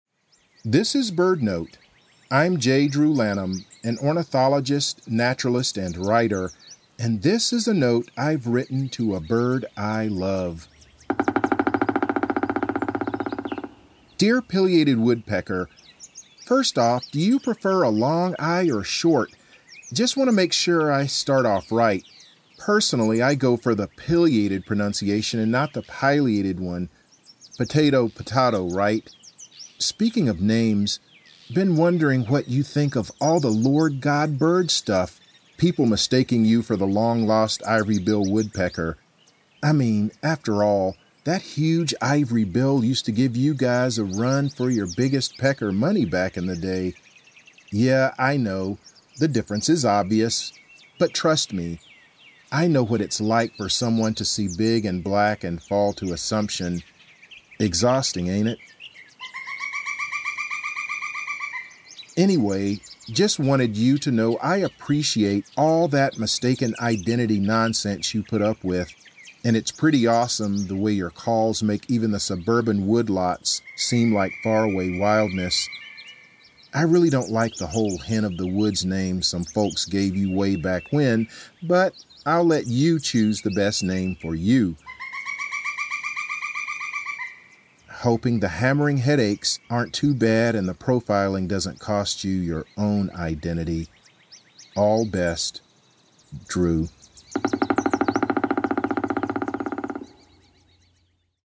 In this episode, ornithologist J. Drew Lanham reads a letter he has written to a Pileated Woodpecker, a large species of woodpecker that is sometimes mistaken for the Ivory-billed Woodpecker.